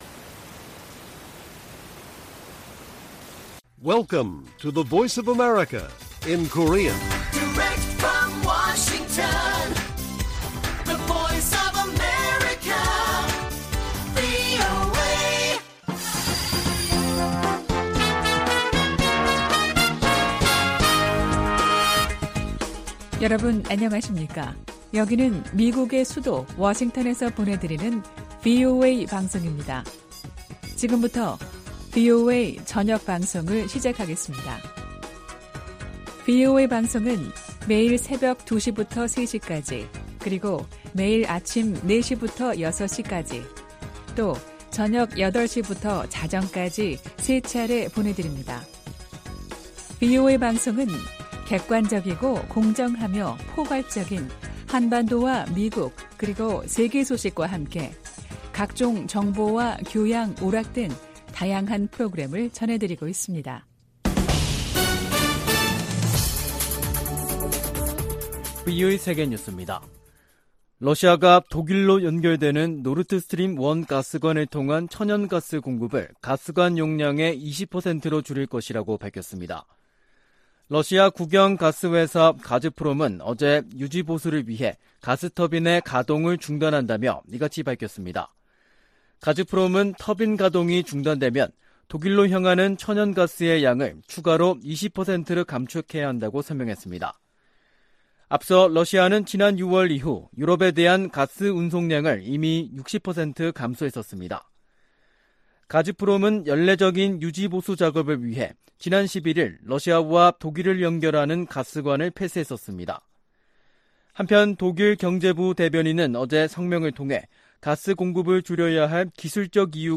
VOA 한국어 간판 뉴스 프로그램 '뉴스 투데이', 2022년 7월 26일 1부 방송입니다. 미 국무부는 모든 가용한 수단을 동원해 북한 악의적 사이버 공격 세력을 추적하고 있다고 밝혔습니다. 아미 베라 미 하원의원은 북한의 핵실험을 한일 갈등 극복과 미한일 관계 강화 계기로 삼아야 한다고 말했습니다. 미군과 한국 군이 세계 최강 공격헬기를 동원한 훈련을 실시했습니다.